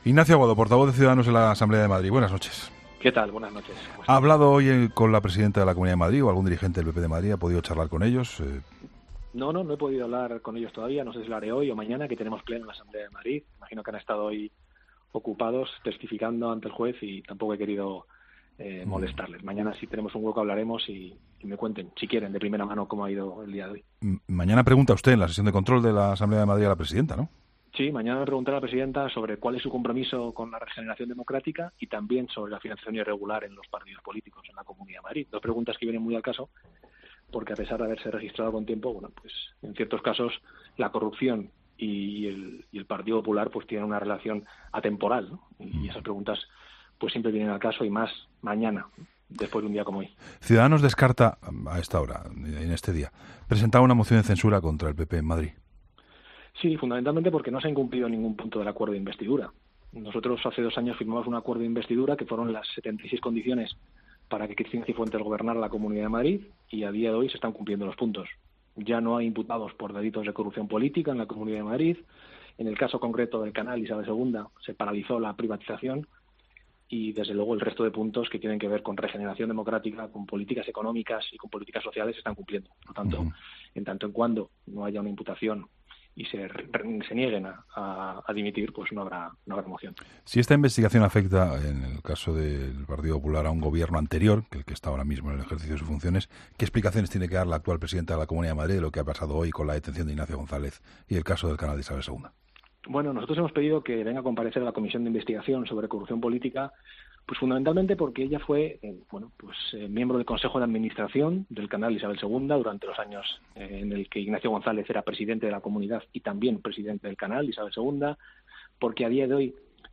Entrevista a Ignacio Aguado